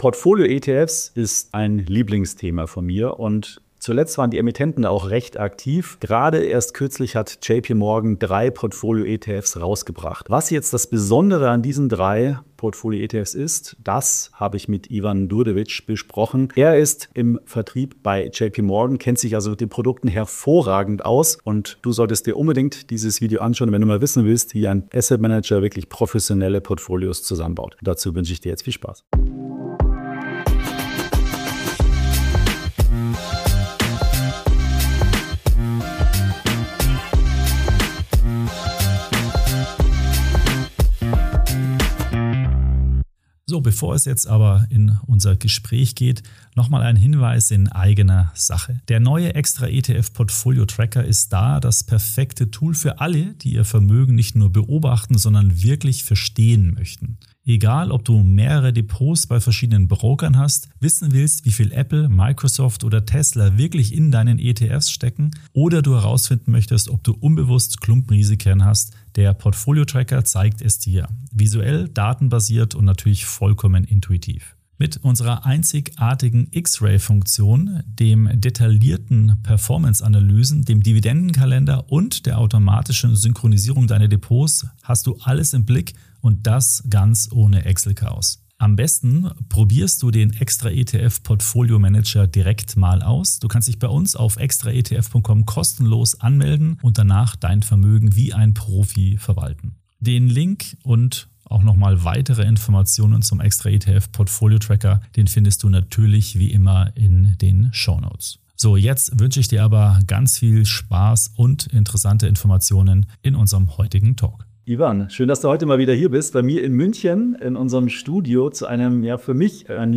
Diese Produkte kombinieren verschiedene Anlageklassen wie Aktien und Anleihen in einem einzigen ETF und steuern die Gewichtung aktiv. In diesem Interview sprechen wir über die Funktionsweise der neuen Strategic Allocation ETFs von JPMorgan, ihre Chancen und Risiken sowie die Rolle solcher Produkte im Portfolio von Anlegern.